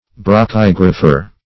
Search Result for " brachygrapher" : The Collaborative International Dictionary of English v.0.48: Brachygrapher \Bra*chyg"ra*pher\, n. A writer in short hand; a stenographer.